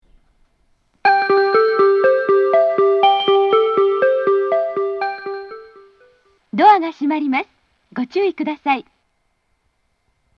メロディー・放送の音量は周辺駅より大きいです。
発車メロディー
こちらも一度扱えばフルコーラス鳴ります。